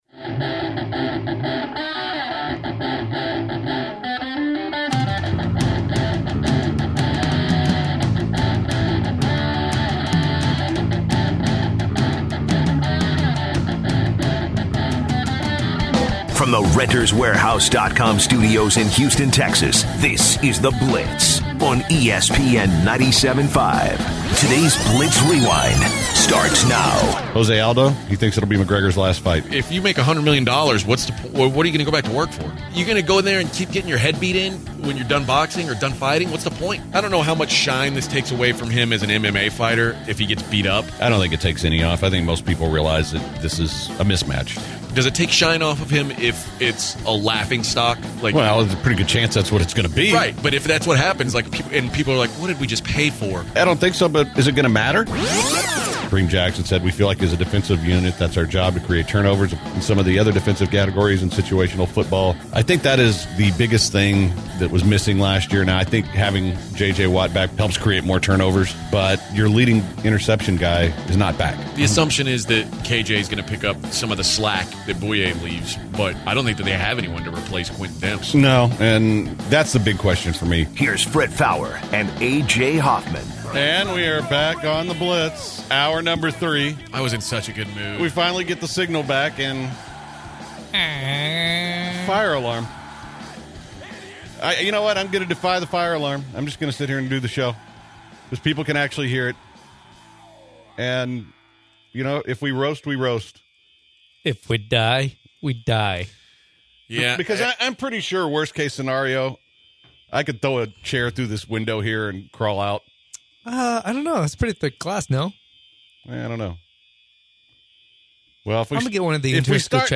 On the third and final hour the guys power through an obnoxious fire alarm.